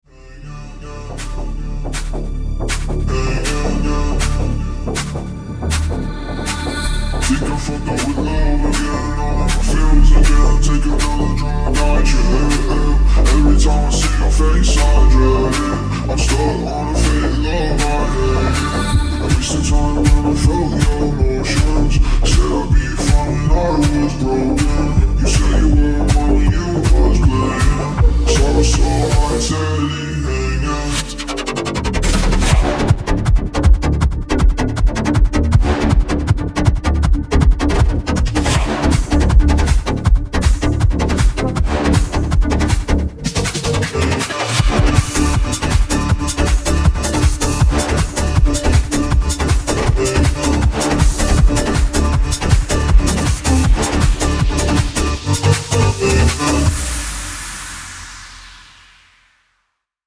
• Качество: 320, Stereo
remix
мощные басы
low bass
Bass House
качающие
G-House
цикличные